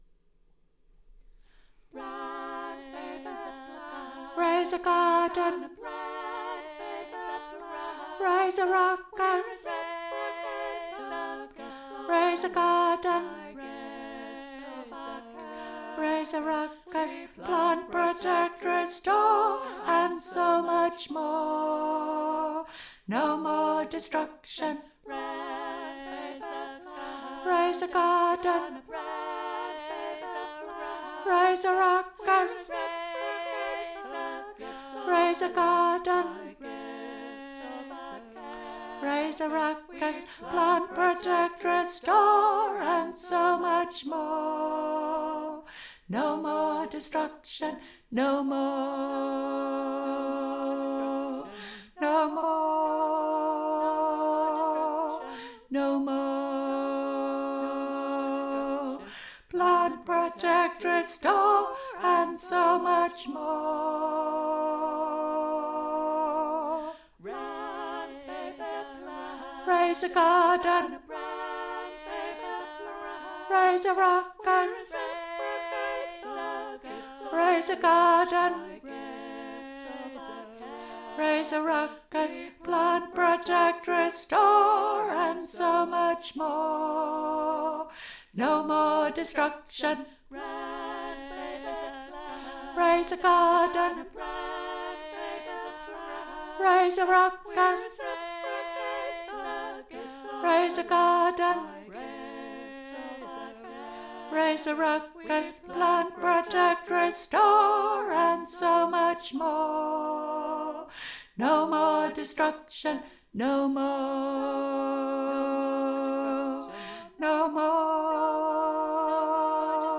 Mid:
plant-baby-plant_mid.mp3